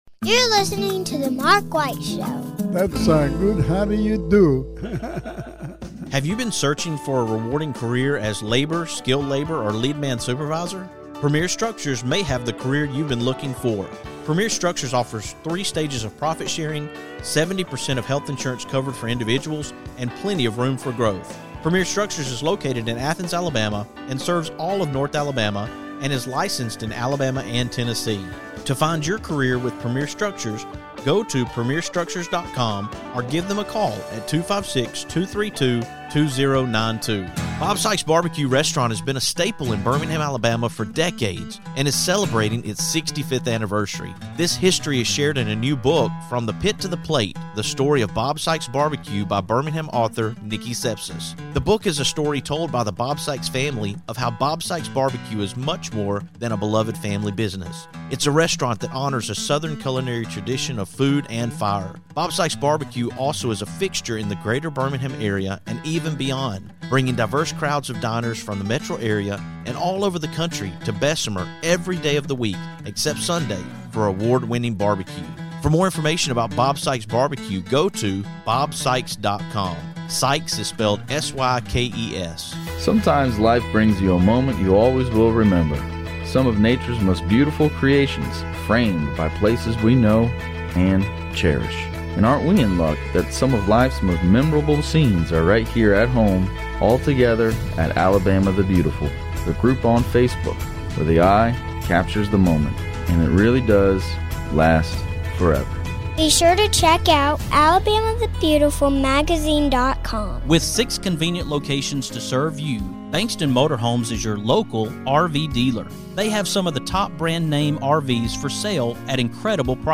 On Saturday, I was able to do an impromptu interview at the Songwriting Workshop Series at STAAR Theatre at Antoinette Hall